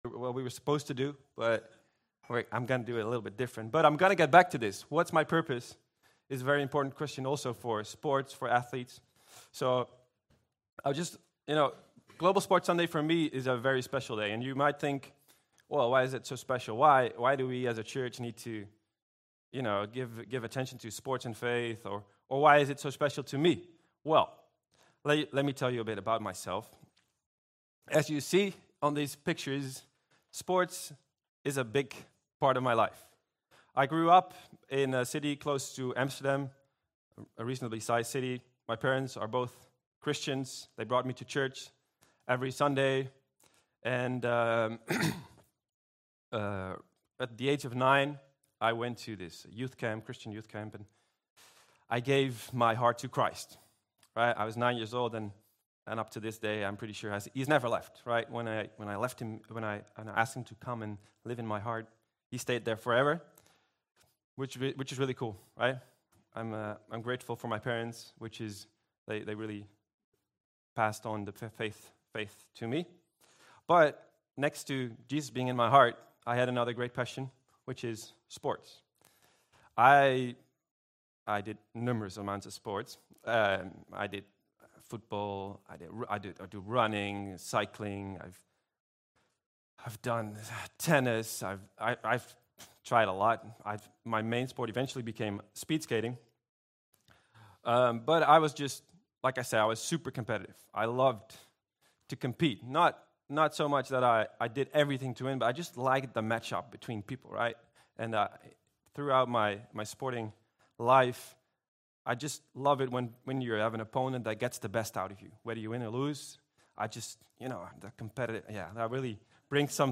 Vineyard Groningen Sermons Identity | Part 3 | What Is My Purpose?